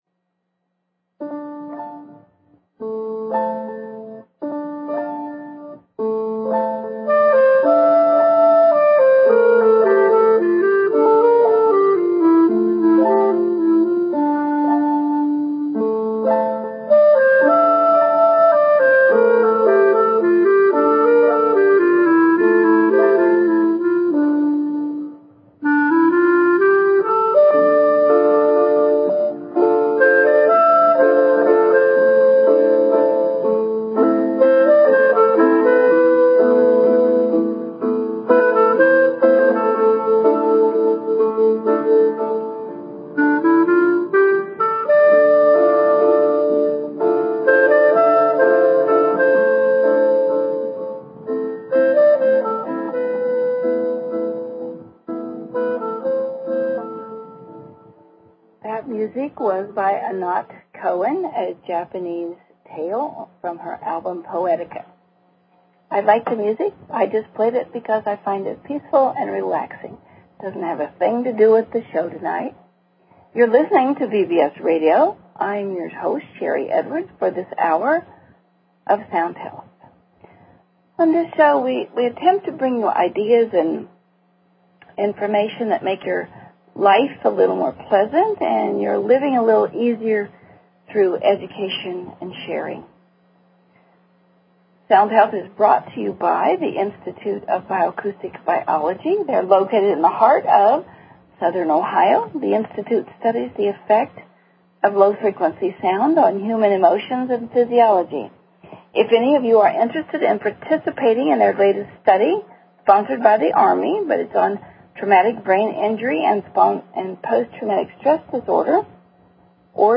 Talk Show Episode, Audio Podcast, Sound_Health and Courtesy of BBS Radio on , show guests , about , categorized as
Subjects included inflammation, healthy diets, exercise, medication, gout, corn syrup, organic foods, fatty acids, obesity, school lunches and supplements. The audience had great questions that provided even more insight.